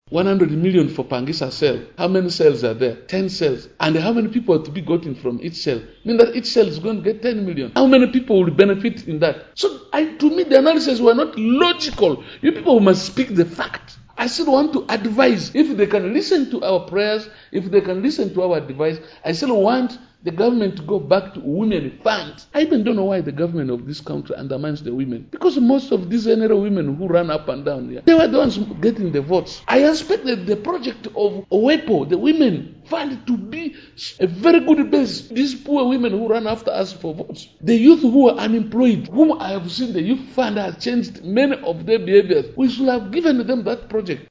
On Monday 28th/11/2022, the Division Mayor Hon Mr. Khemis Muzaid while talking to our reporter noted that the project is having a lot of irregularities and also believes that the project was proposed by some people who have intention of making huge commission that will make the project to fail.